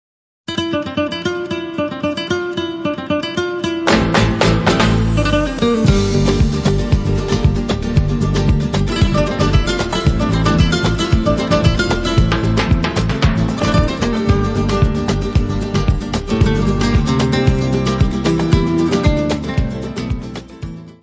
guitarra, voz e palmas
: stereo; 12 cm
Music Category/Genre:  World and Traditional Music